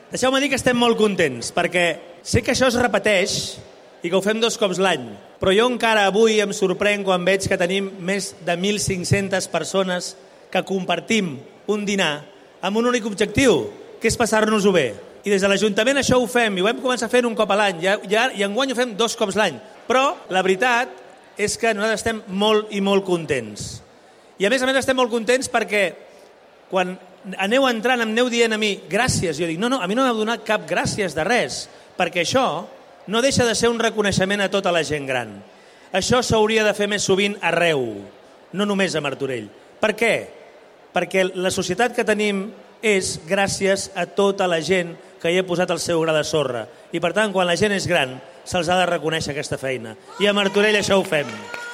Xavier Fonollosa, alcalde de Martorell
Parlaments-Alcalde-Trobada-Tardor-Gent-Gran-01.mp3